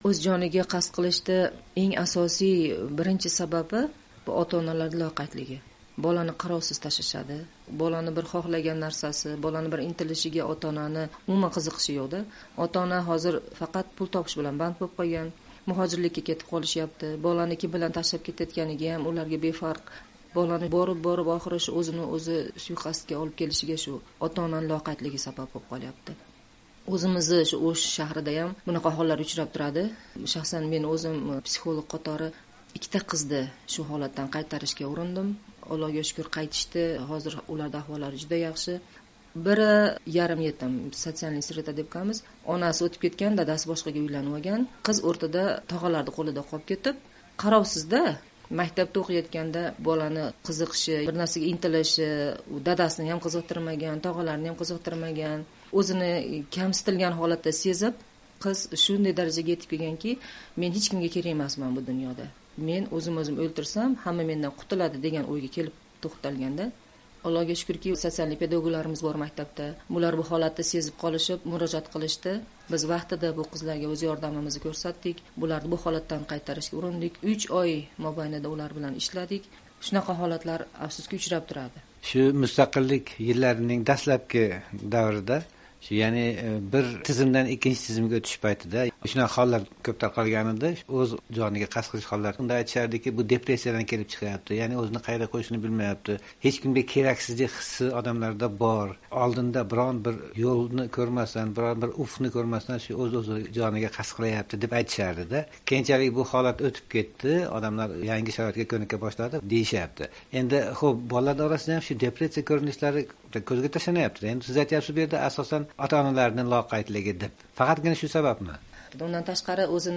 Suhbatdosh so'zlariga ko’ra, o’smirlarda hayotdan, uni o’rganishdan zavqlanish, o’ziga ishonch, iroda kabi sifatlarni shakllantirish uchun ularni foydali mashg’ulotlar bilan band qilmoq lozim.